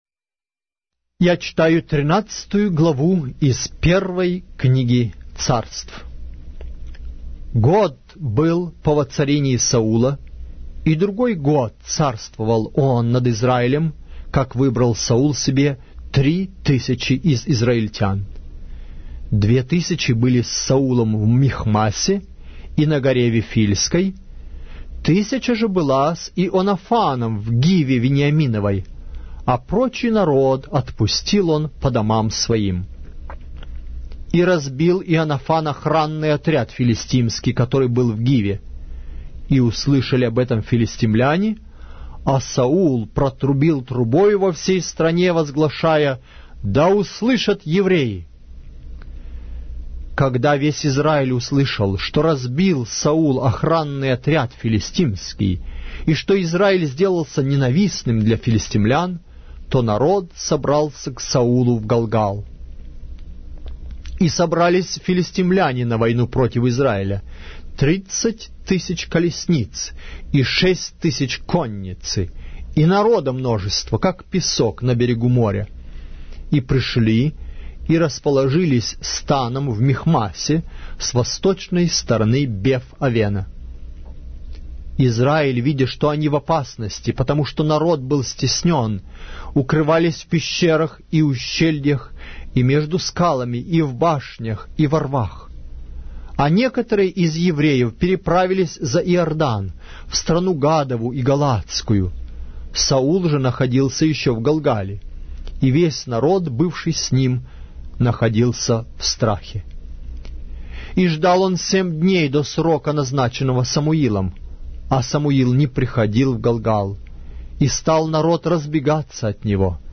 Глава русской Библии с аудио повествования - 1 Samuel, chapter 13 of the Holy Bible in Russian language